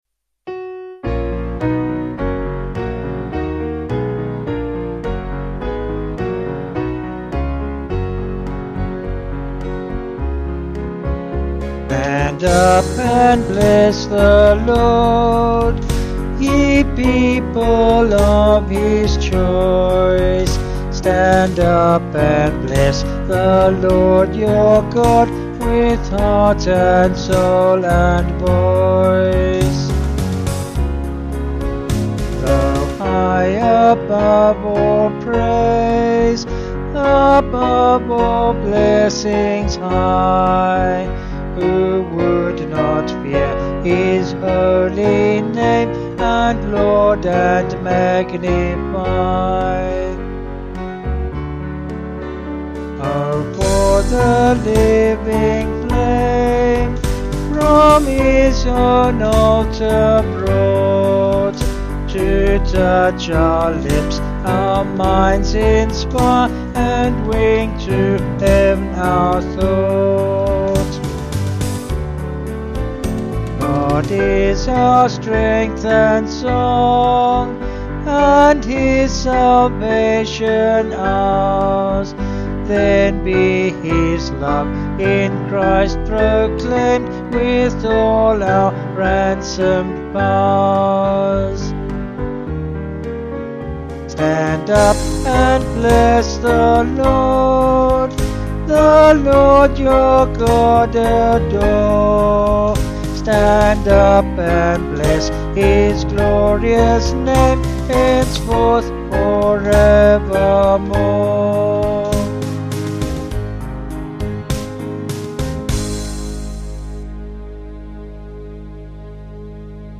(BH)   5/Gb-G-Ab
Vocals and Band   264.6kb Sung Lyrics